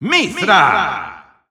Announcer pronouncing Mythra's name in Spanish.
Mythra_Spanish_Announcer_SSBU.wav